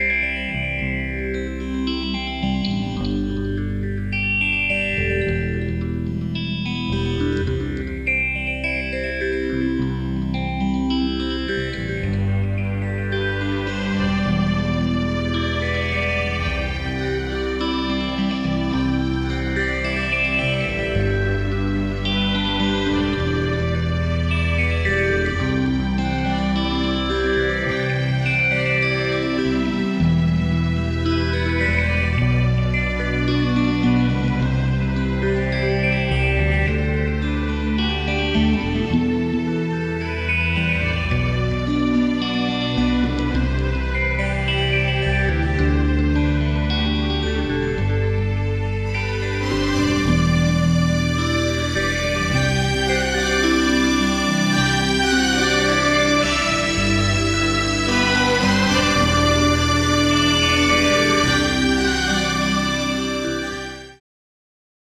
Recorded at L.A. Studios – Lambaré, Paraguay
Keyboards and Piano
Flute